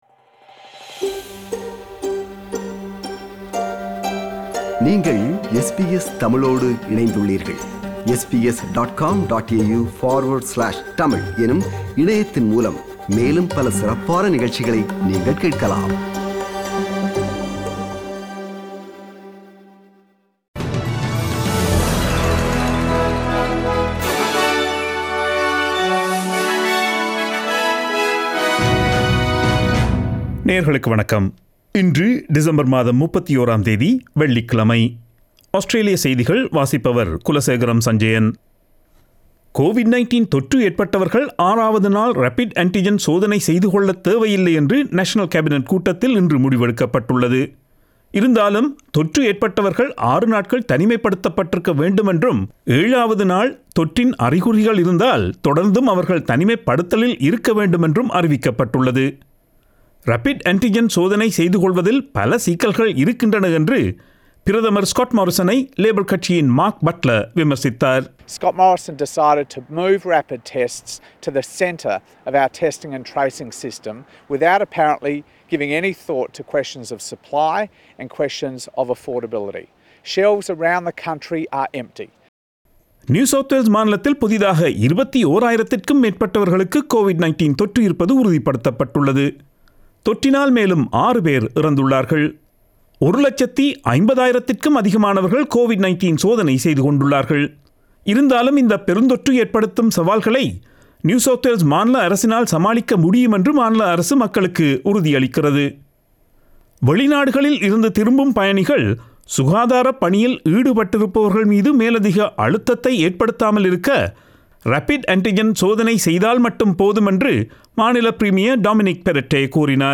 Australian news bulletin for Friday 31 December 2021.